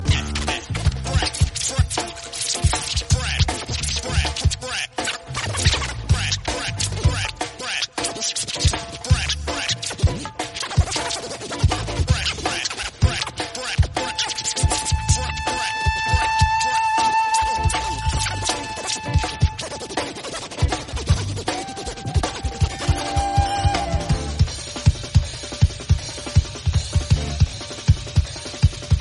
Dj Scratching Sound Effect Free Download
Dj Scratching